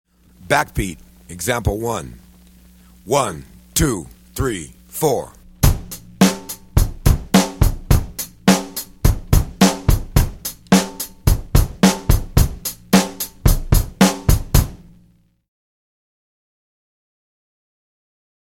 Méthode pour Batterie - Batterie et Percussions